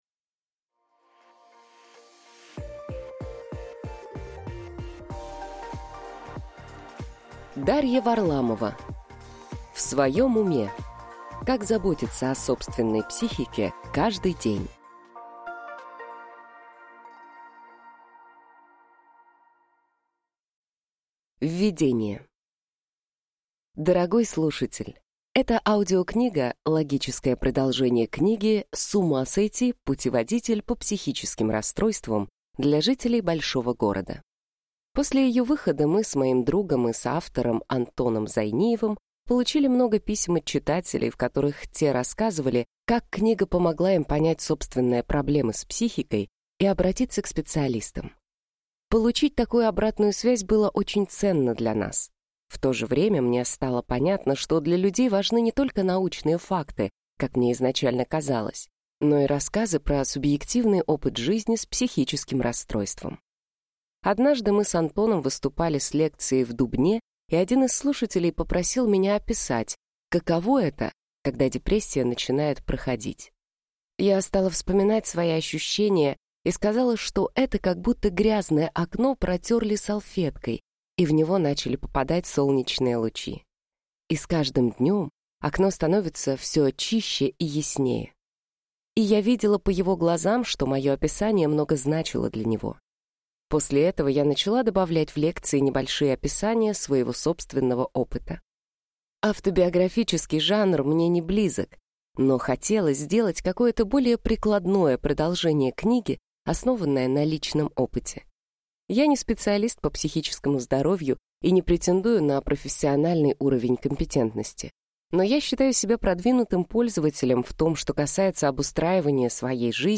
Аудиокнига В своем уме: Как заботиться о собственной психике каждый день | Библиотека аудиокниг